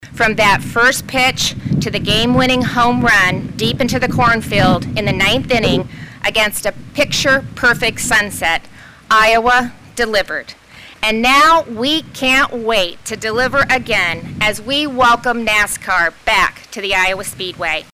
NASCAR hall of Famer Rusty Wallace, spearheaded the effort to get the track built in Newton in 2006 and was excited by the announcement.